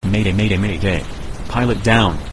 pilotdown.ogg